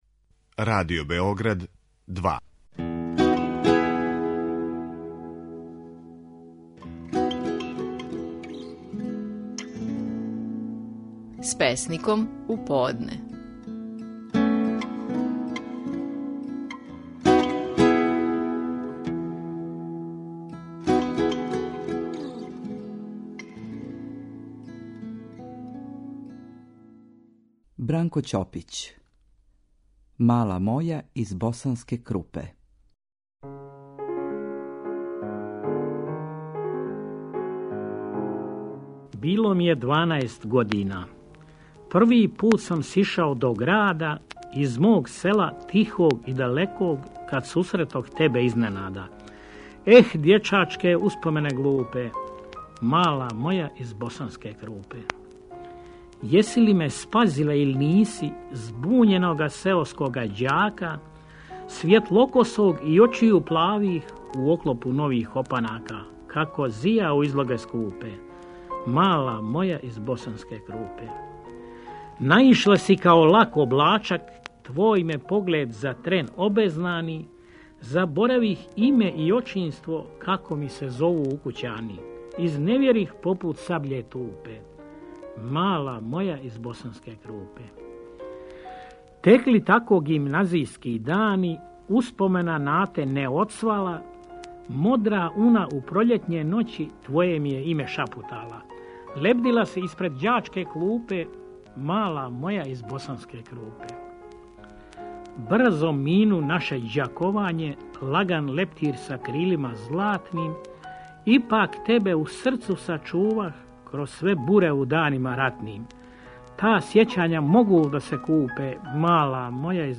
Наши најпознатији песници говоре своје стихове
У данашњој емисији слушамо како је Бранко Ћопић говорио стихове своје песме "Мала моја из Босанске Крупе".